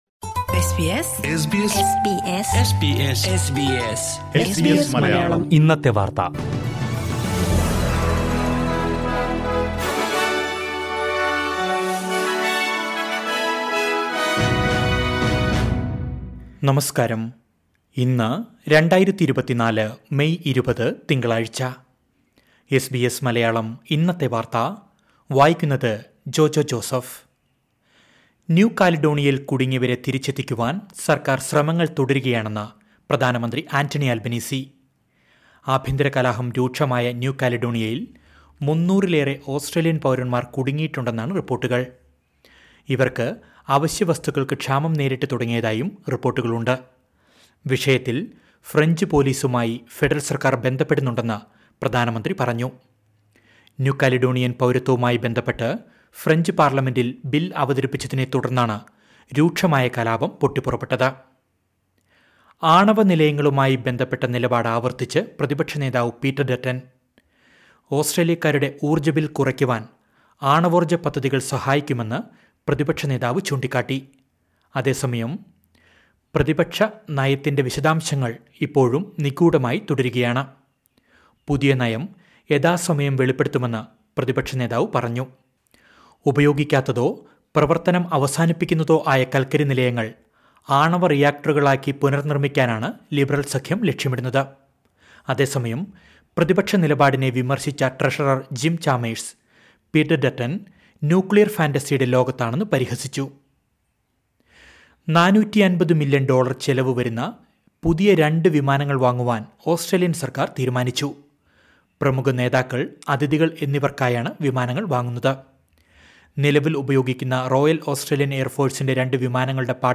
2024 മെയ് 20ലെ ഓസ്‌ട്രേലിയയിലെ ഏറ്റവും പ്രധാന വാര്‍ത്തകള്‍ കേള്‍ക്കാം...